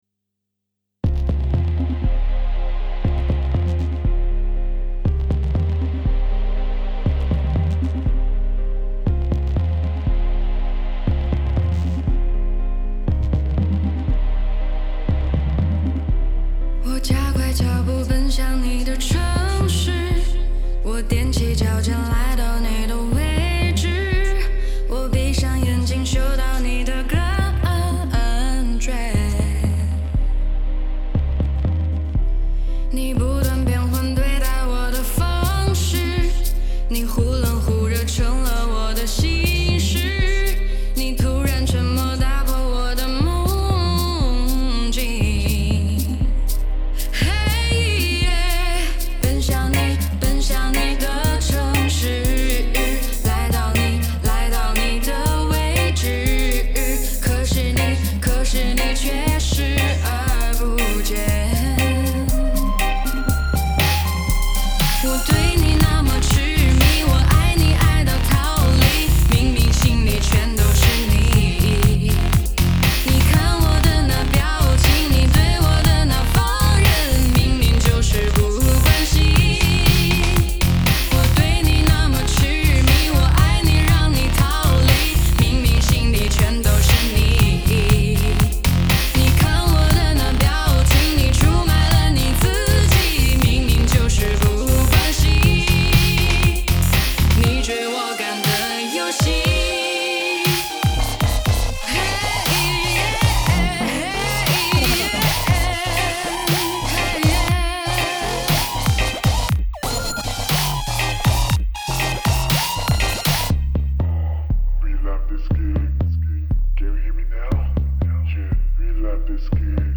动感十足的电子曲风新歌